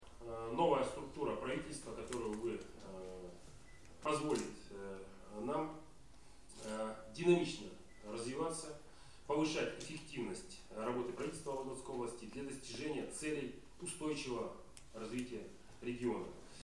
Олег Кувшинников рассказывает об изменениях в структуре Правительства